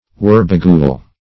Search Result for " wurbagool" : The Collaborative International Dictionary of English v.0.48: Wurbagool \Wur"ba*gool\, n. (Zool.) A fruit bat ( Pteropus medius ) native of India.